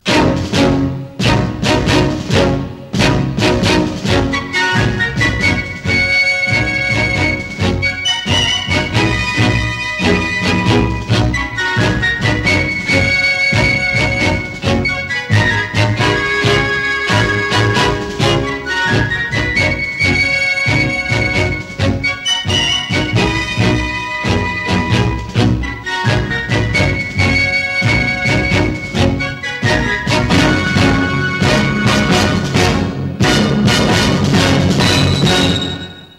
theme tune